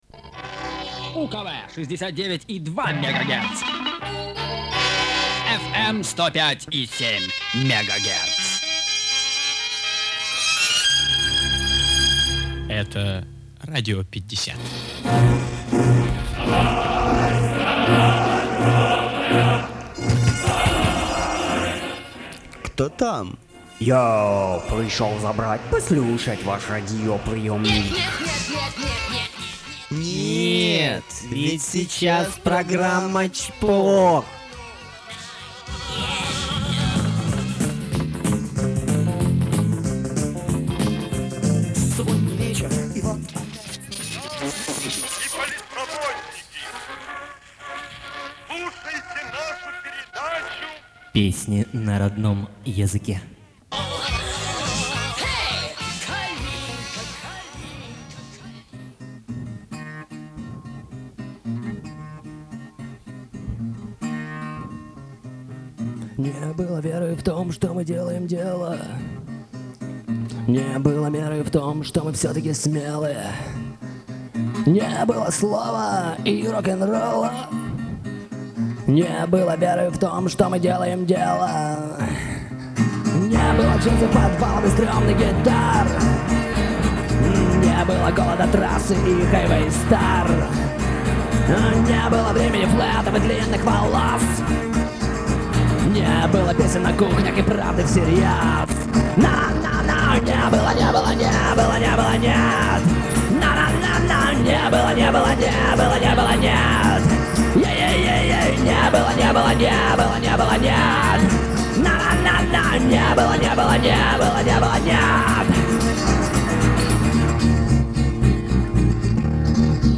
вокал, ак. гитара